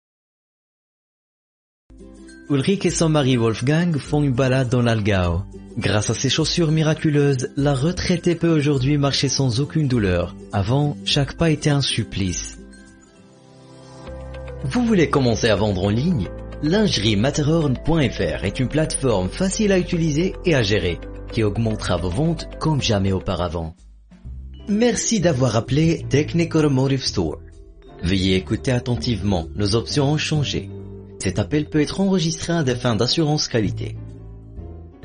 配音风格： 大气 知性 浑厚 温暖 轻松 稳重 亲切 清新 自然 甜美 自然 甜美